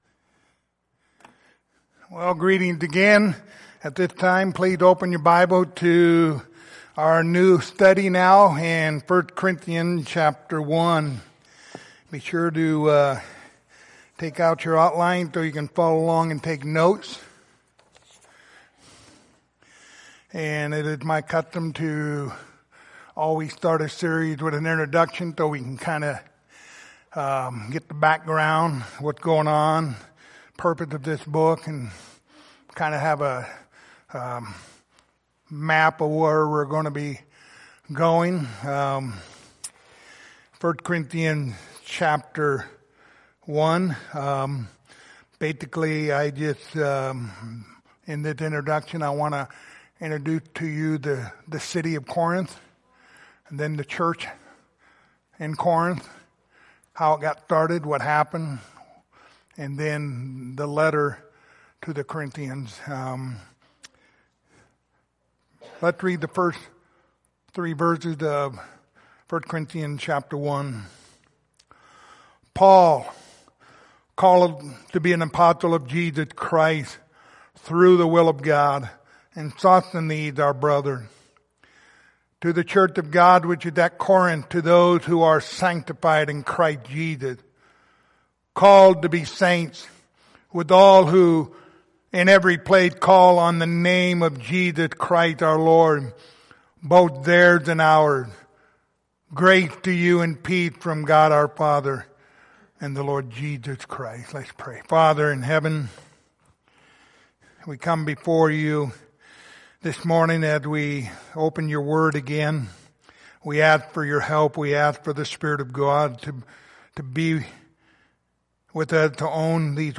Passage: 1 Corinthians 1:1-3 Service Type: Sunday Morning